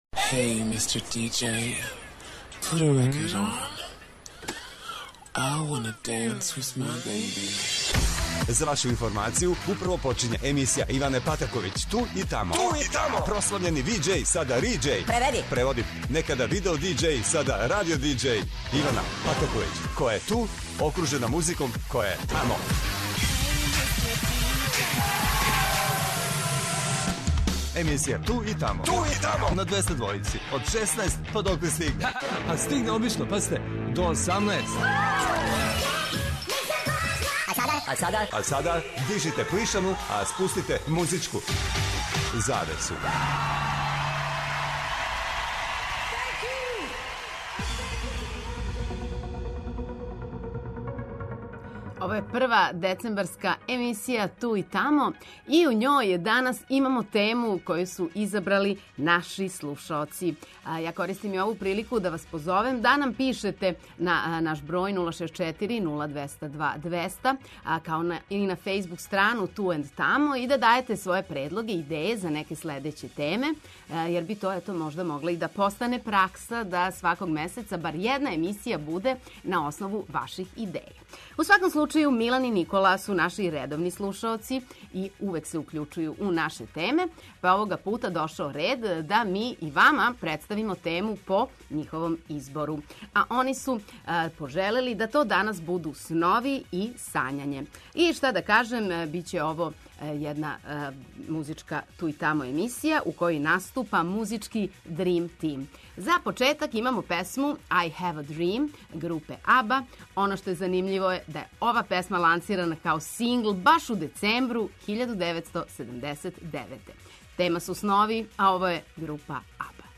То значи да ће се на програму Двестадвојке завртети велики домаћи и страни хитови који у насловима имају слатке, луде, опасне и неухватљиве снове.
Очекују вас велики хитови, страни и домаћи, стари и нови, супер сарадње, песме из филмова, дуети и још много тога.